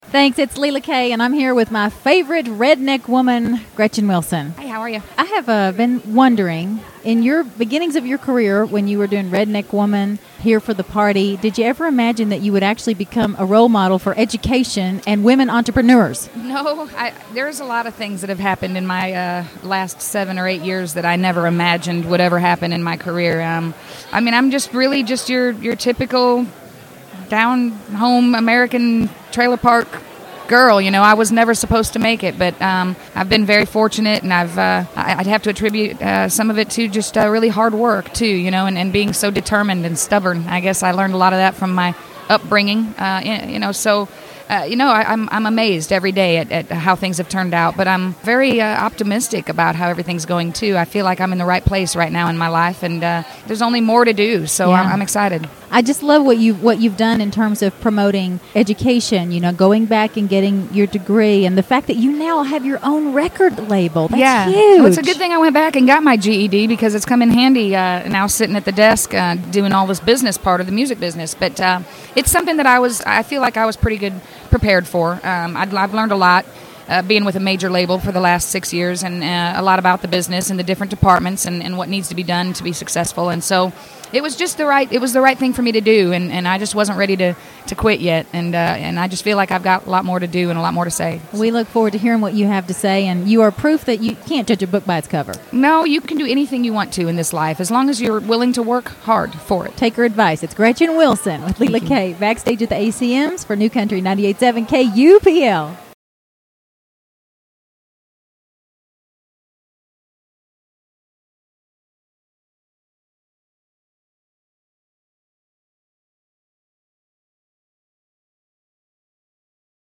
Gretchen Wilson Interview 2010 ACM Awards